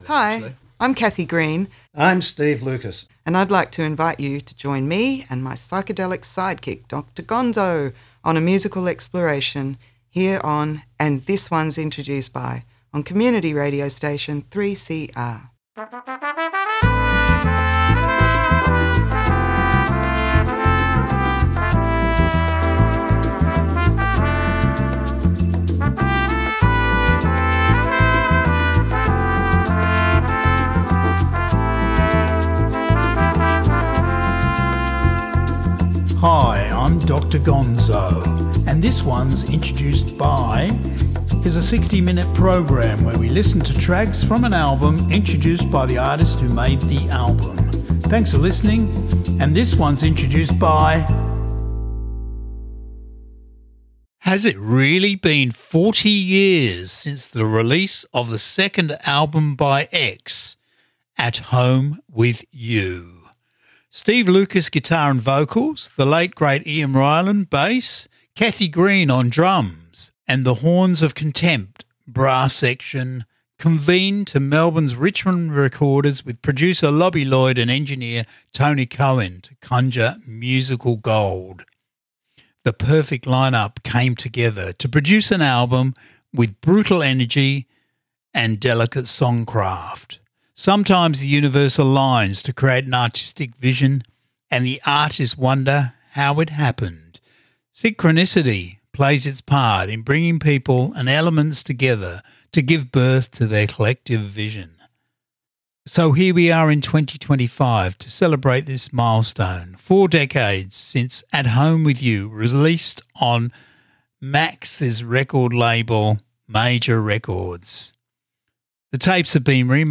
guitar and vocals
brass section